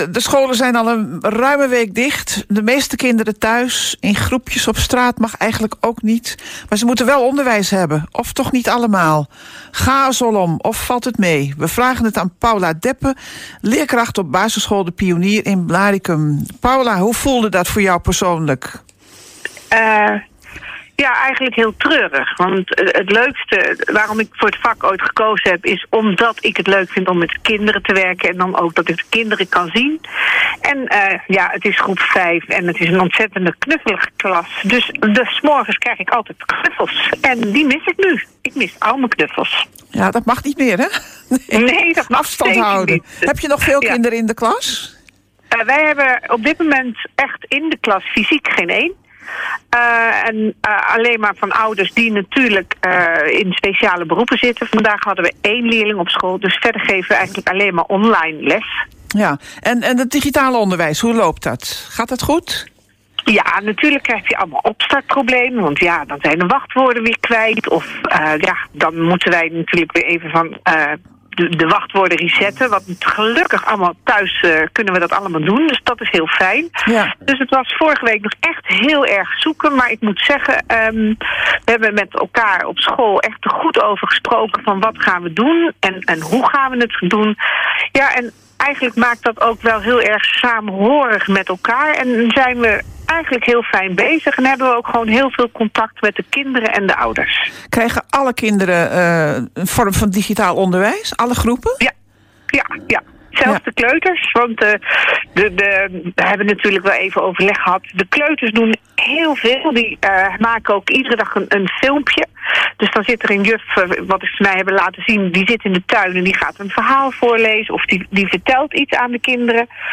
korte gesprekken